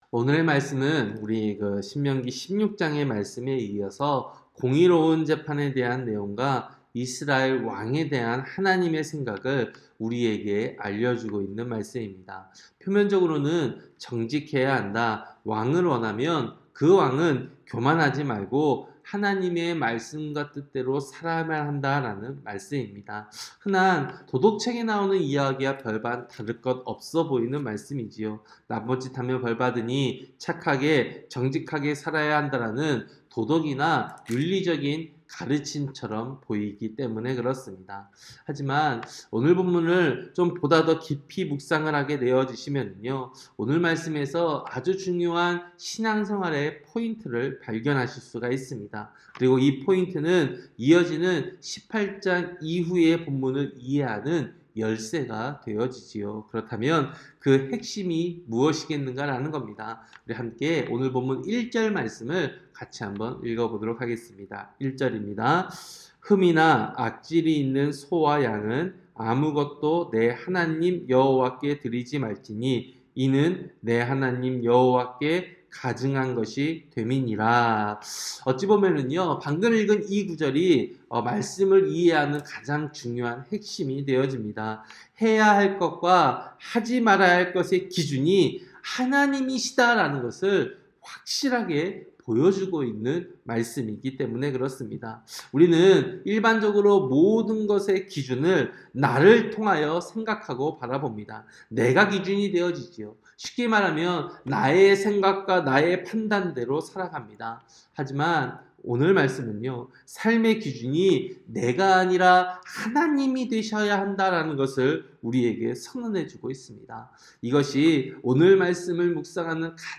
새벽설교-신명기 17장